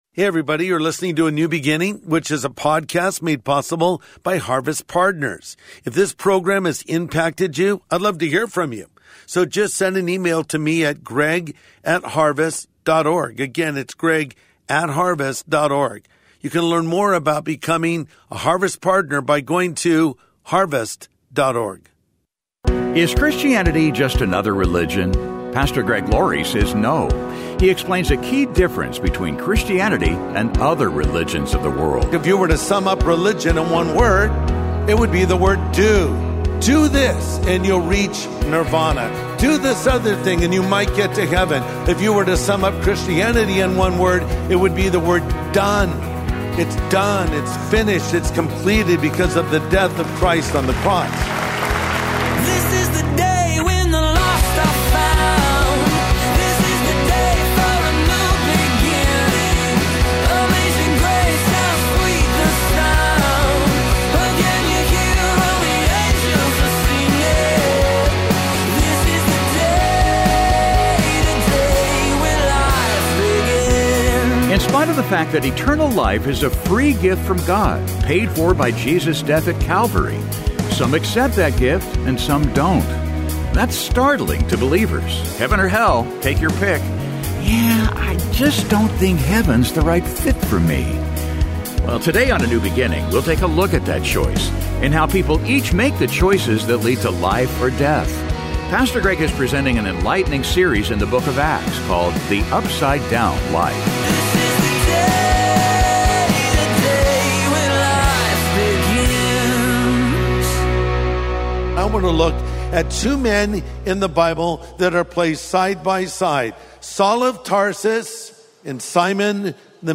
Pastor Greg Laurie is presenting an enlightening series in the book of Acts called The Upside-Down Life.